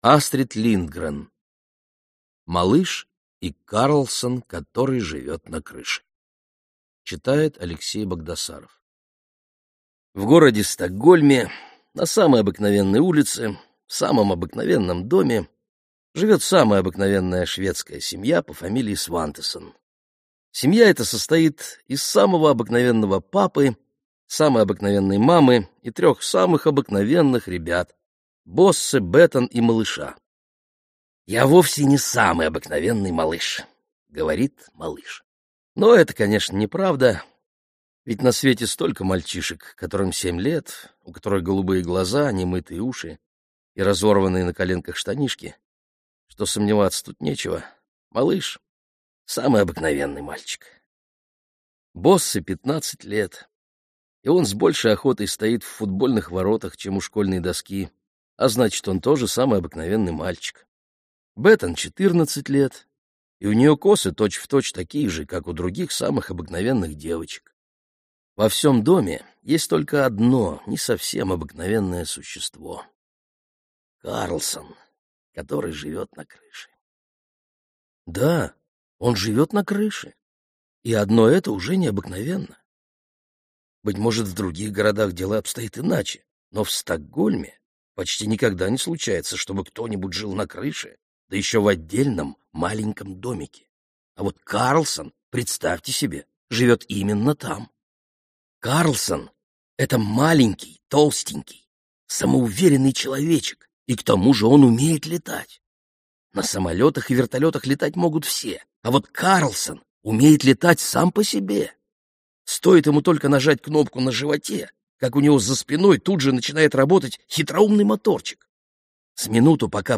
Аудиокнига Малыш и Карлсон, который живет на крыше - купить, скачать и слушать онлайн | КнигоПоиск